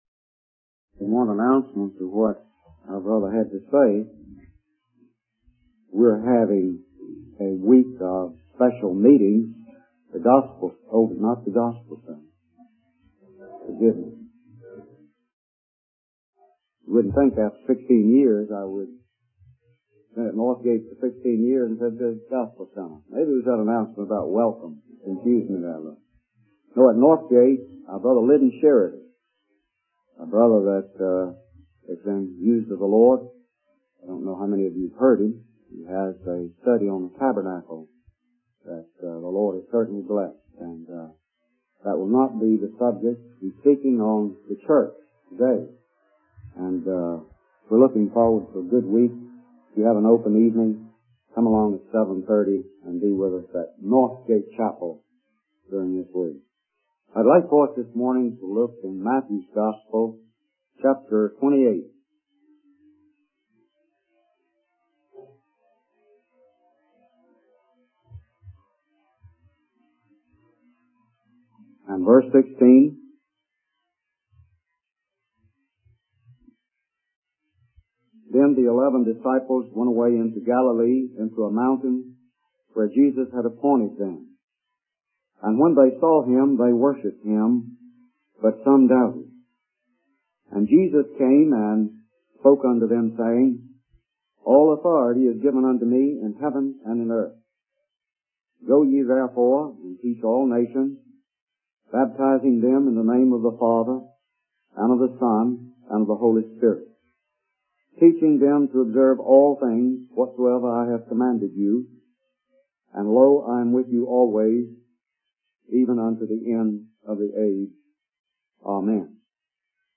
In this sermon, the speaker emphasizes the power of the gospel and how it can transform lives. He uses the analogy of a hunter who knows the power of his weapon and has trophies to show for it.